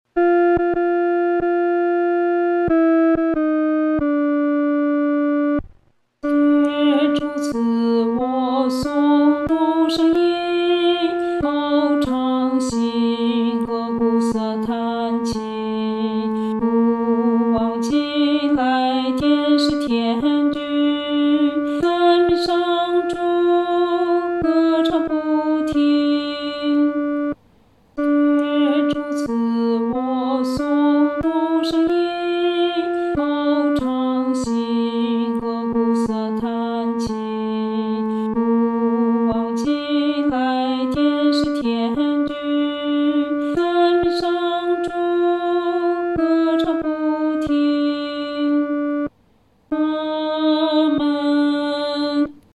合唱
女低